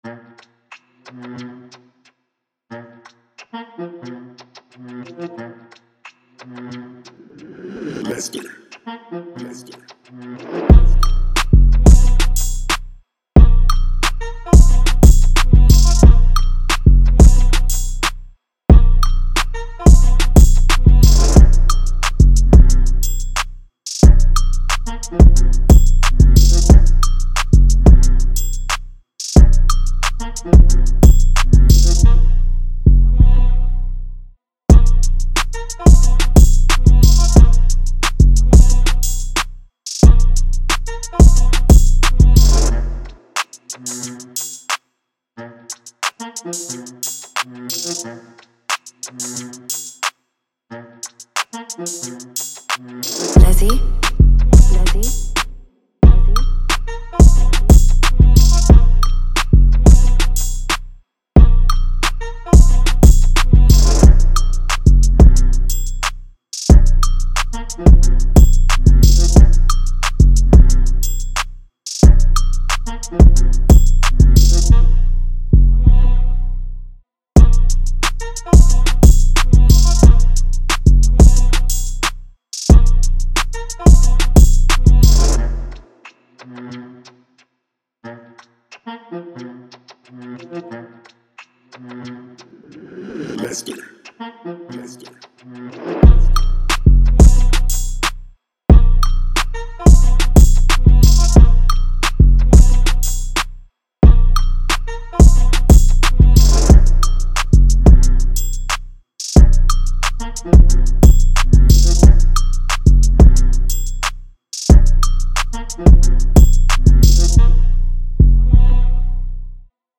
Auch keine Runde...